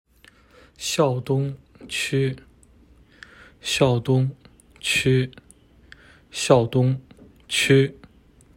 Here is the pronunciation of my first name and last name.